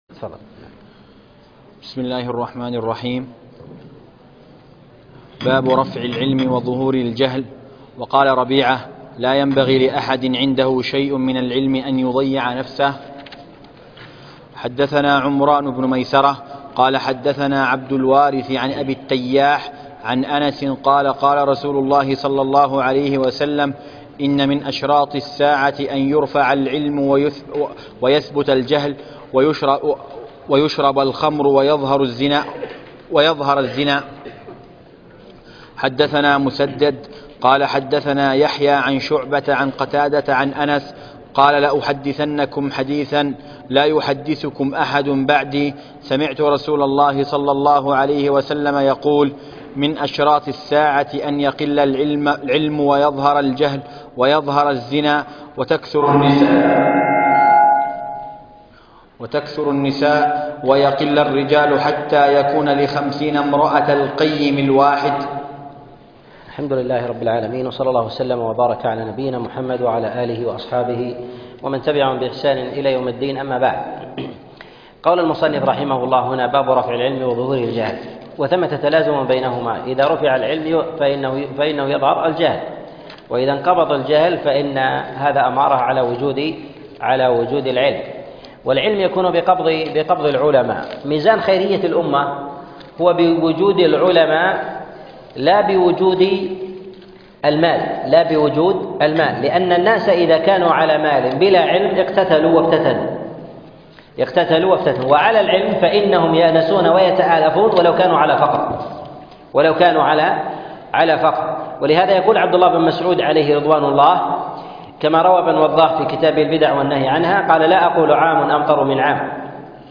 شرح كتاب العلم من صحيح البخاري الدرس 3 - الشيخ عبد العزيز بن مرزوق الطريفي